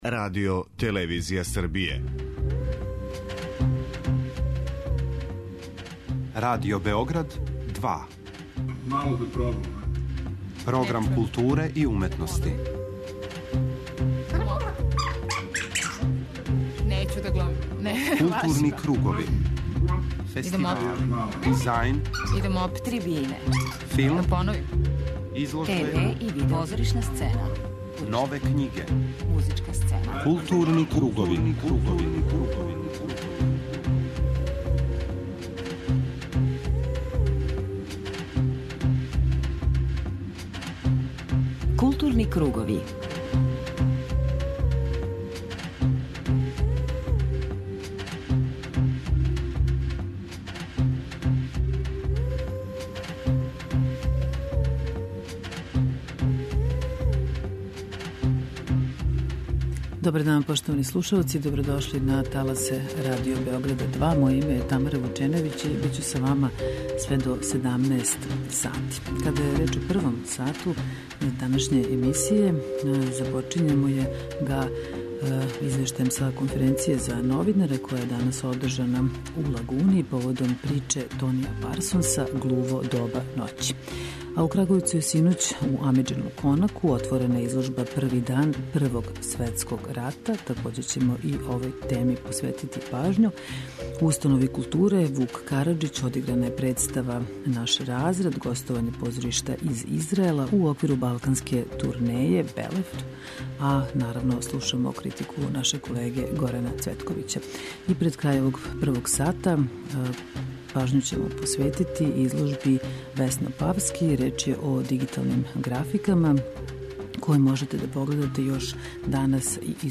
уживо